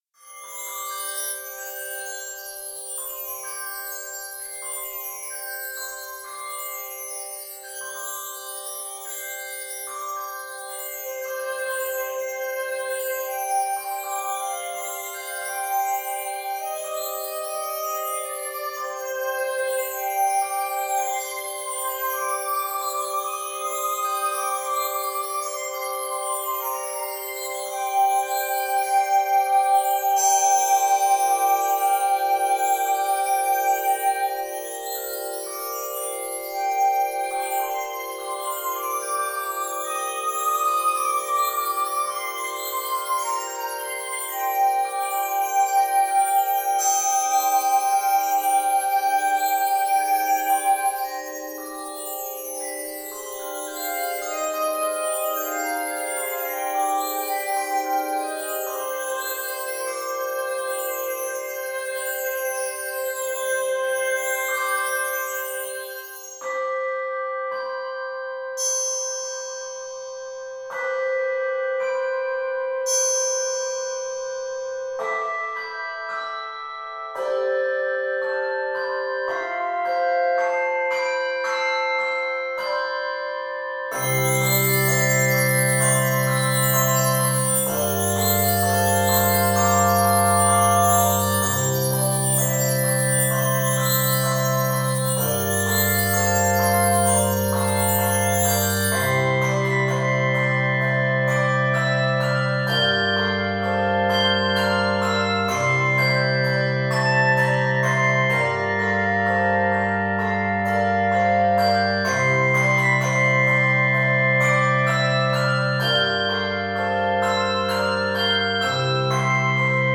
Keys of C Major and F Major.